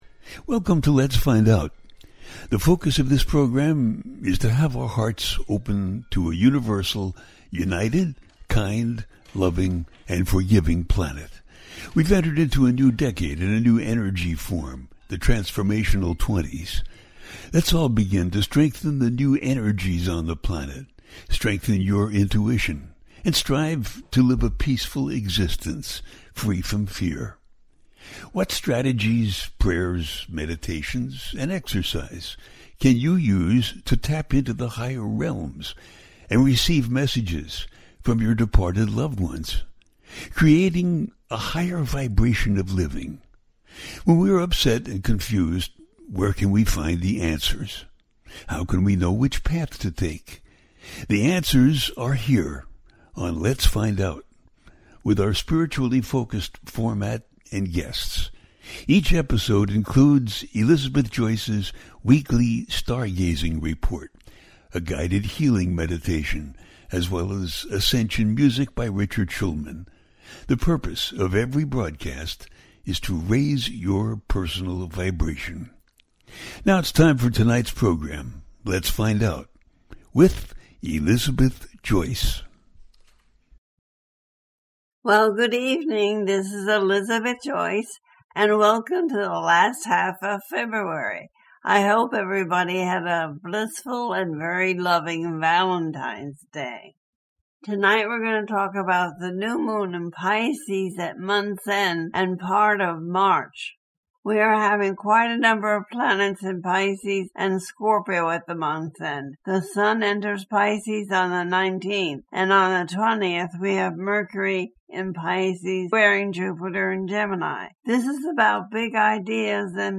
The Pisces New Moon And The New Era - A teaching show
The listener can call in to ask a question on the air.
Each show ends with a guided meditation.